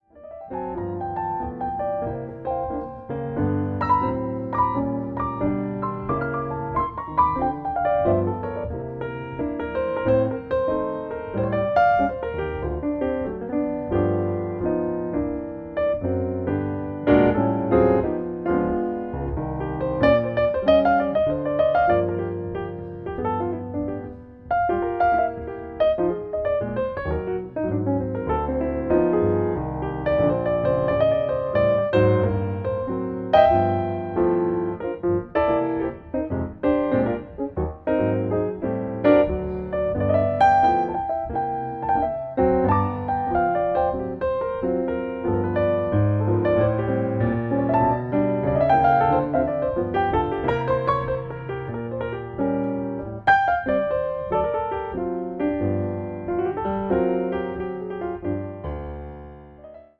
DSD Recording